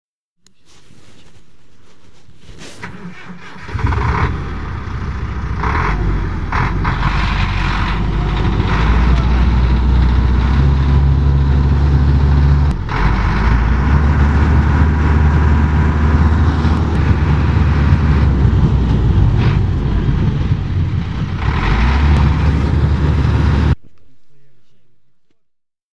Звуки фуры
Глухой звук мотора старого грузовика